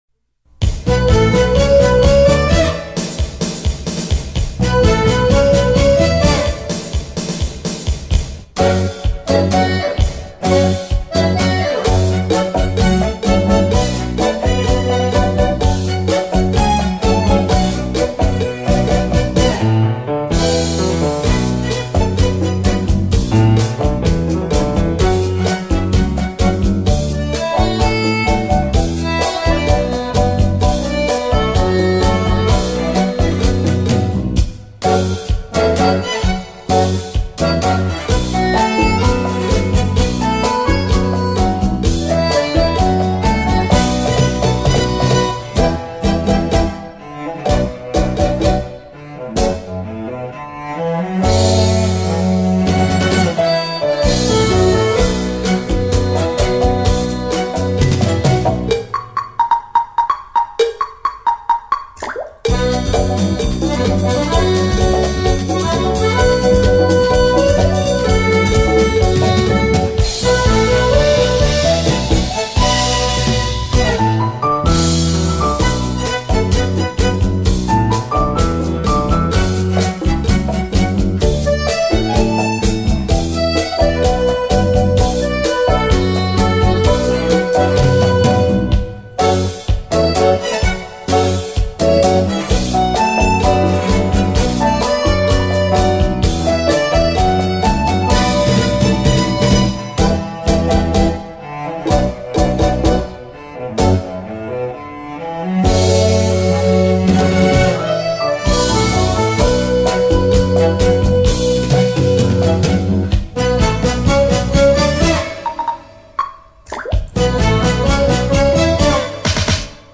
閒適優雅的音符，完美傳真的音質，呈獻在您的耳際
優美動聽的舞蹈旋律讓人沉醉其中...
恰恰